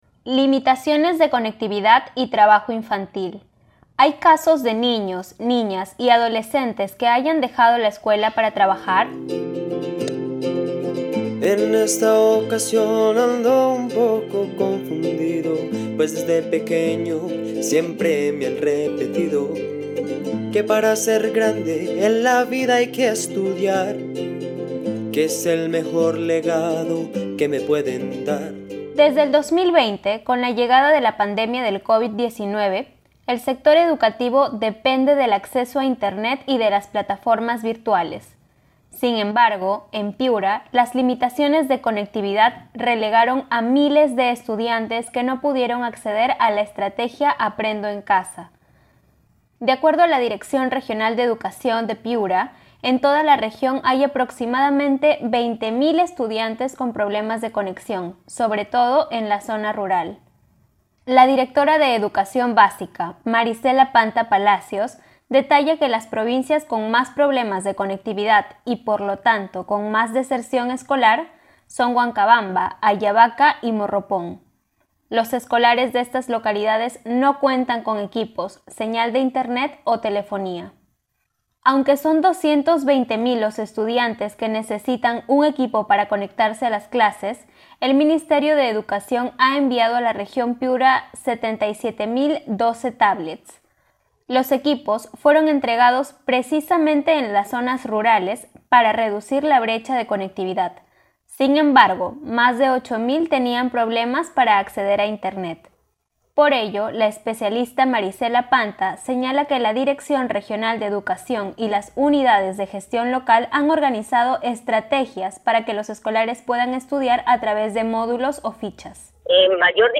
Un informe